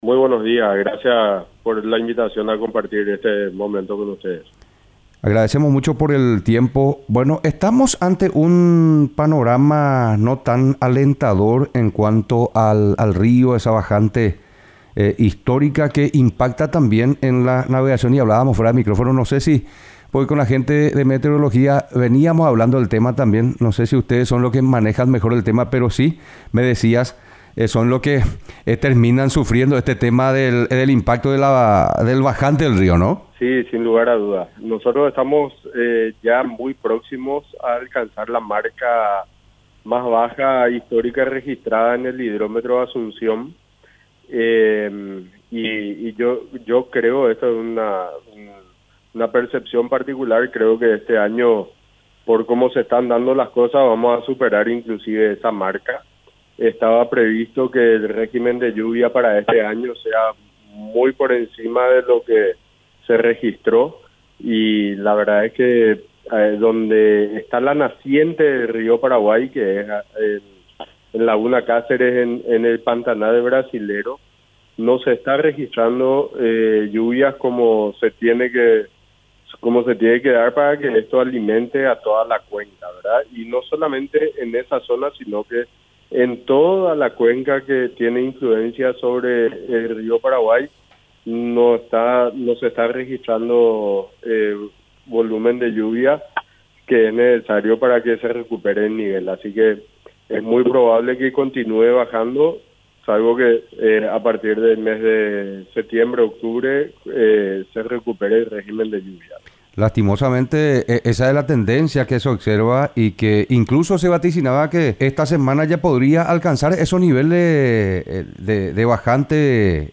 Entrevistas / Matinal 610 Bajante del rio y su impacto en la navegabilidad Aug 21 2024 | 00:11:39 1x 00:00 / 00:11:39 Subscribe Share RSS Feed Share Link Embed